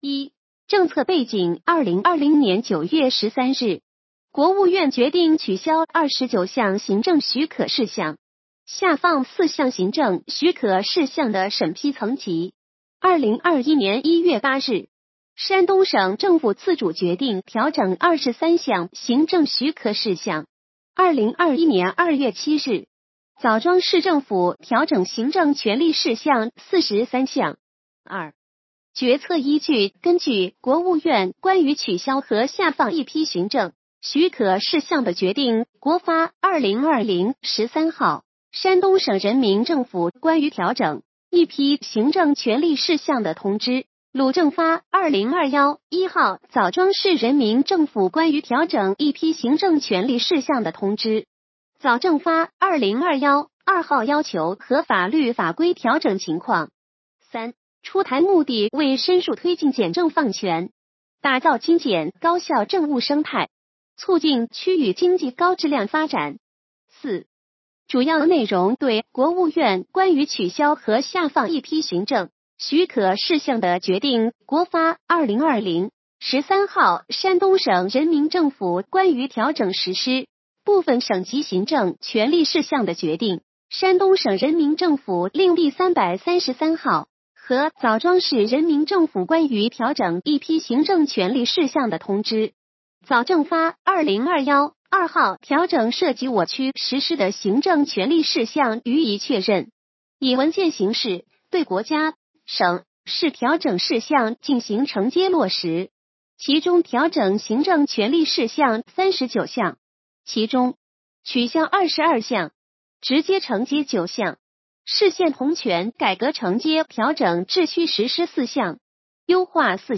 语音解读：山亭区人民政府关于调整一批行政权力事项的通知